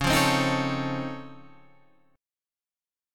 Db+M9 chord